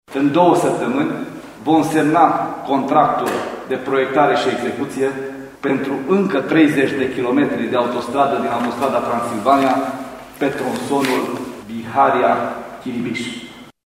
Ministrul Transporturilor, Lucian Bode a declarat astăzi la Târgu Mureș că 2020 este și anul Autostrăzii Transilvania, în contextul în care a participat la semnarea contractului pentru “Construcția sectorului de Autostradă Târgu Mureș – Ungheni și Drum de Legătură”.